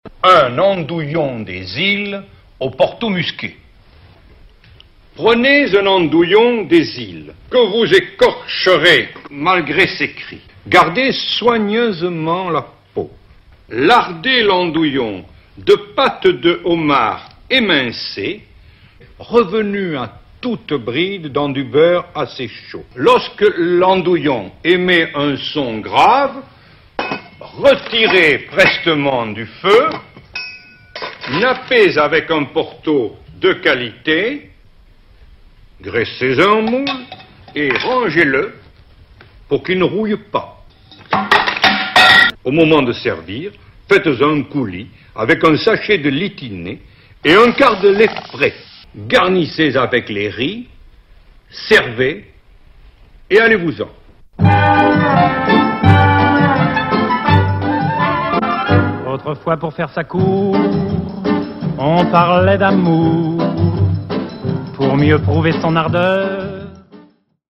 La recette de l'andouillon dans une emission télévisée.